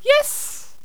princess_ack3.wav